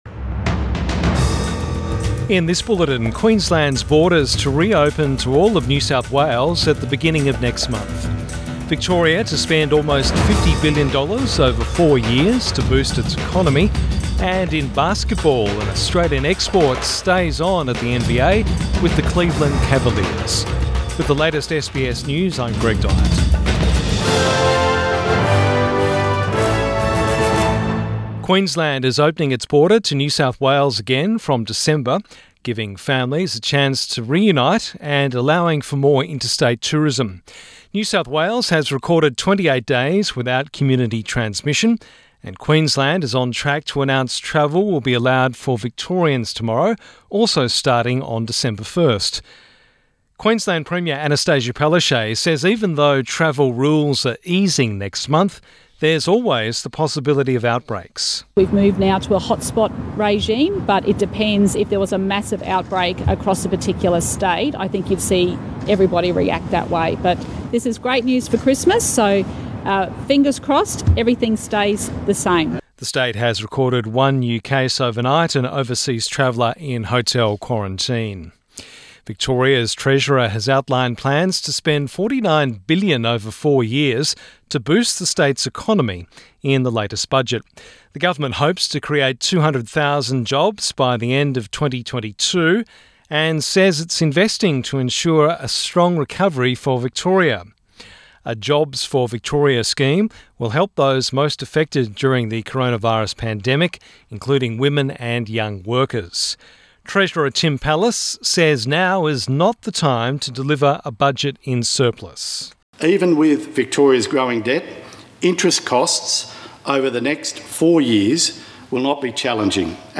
PM bulletin 24 November 2020